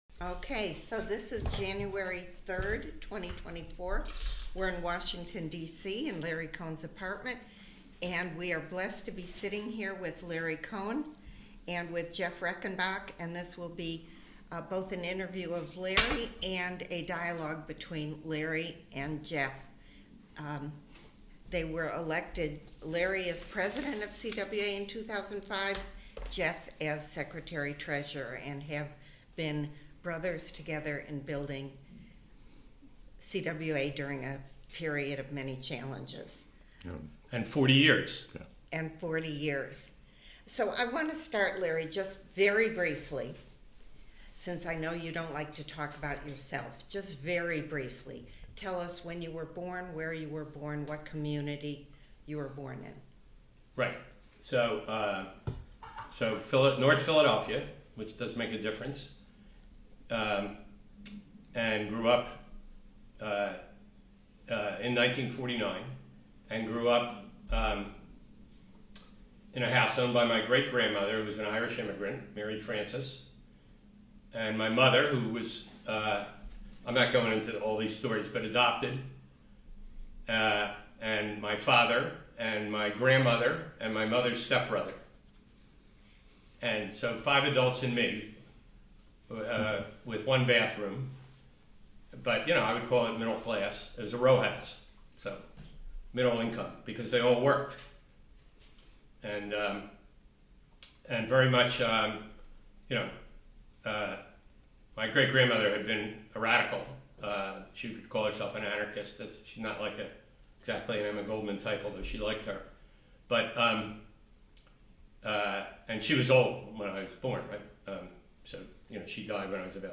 larry_cohen_oral_history.mp3